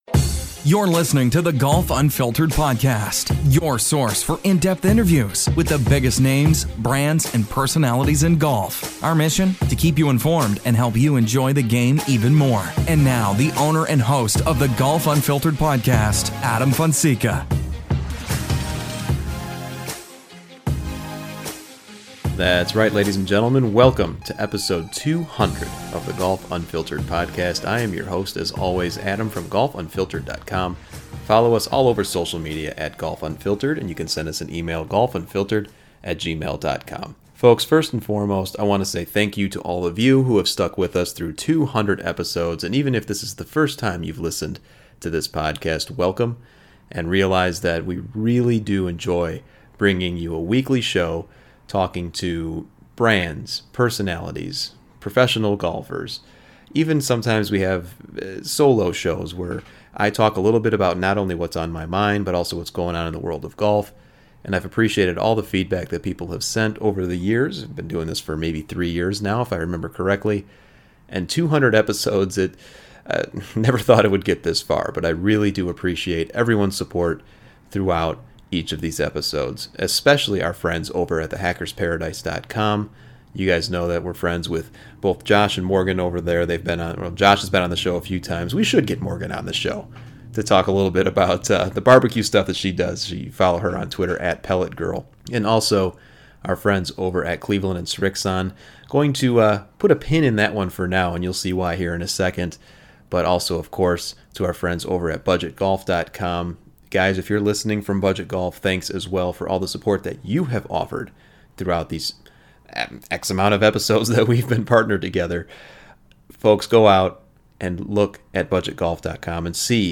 The show brings new and interesting topics and interviews from the world of golf and we are honored to have it as part of our lineup on The Hackers Paradise Podcast Network.